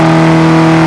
mr2gt_low.wav